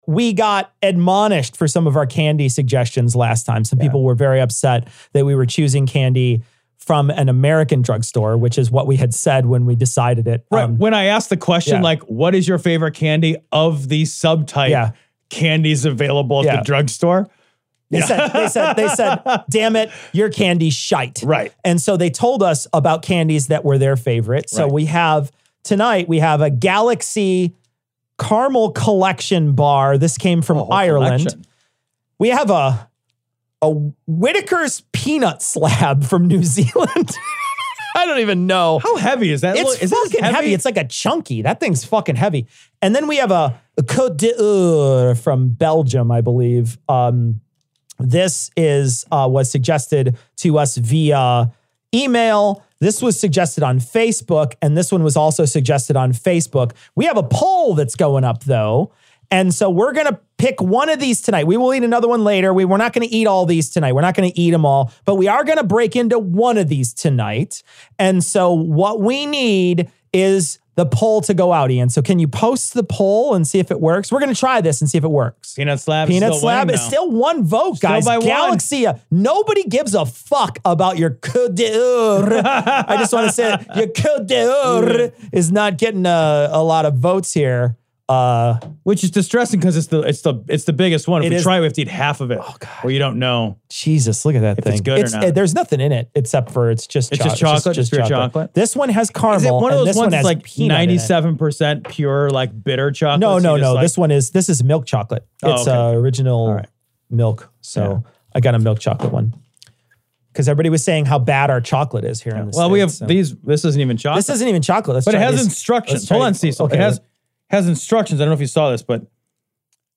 LIVESTREAM AUDIO (Patreon) Published: 2020-02-06 03:43:27 Imported: 2024-12 Tags: Livestream livestream audio ⚑ Flag Downloads Download LIVESTREAM 2020-01-30.mp3 Content JOIN US FOR OUR LIVESTREAM NOW WITH INTERNET....